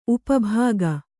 ♪ upa bāga